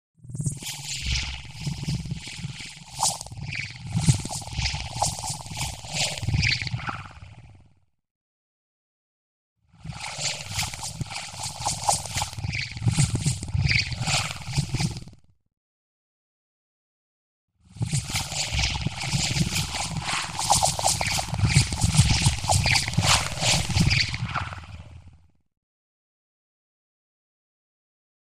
Alien Swarm Flies By | Sneak On The Lot
Synth Tones, Sweeps And Rumbles, Mechanical.